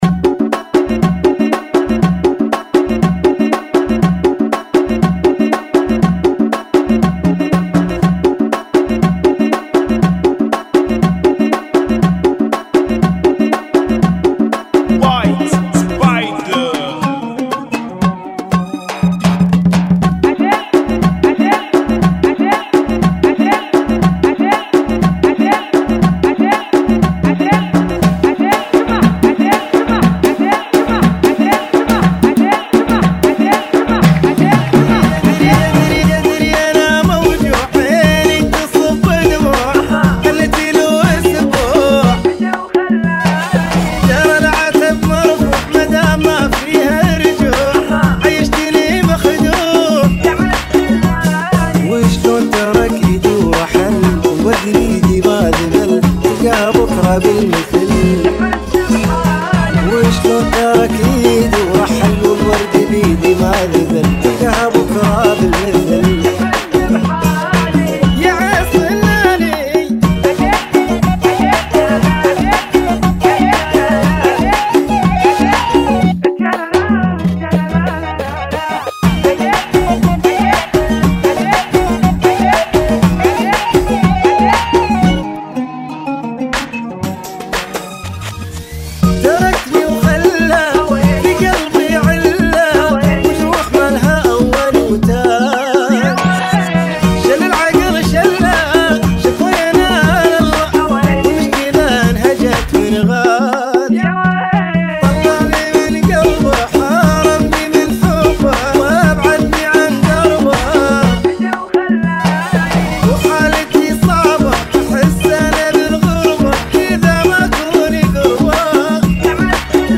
Funky [ 120 Bpm ]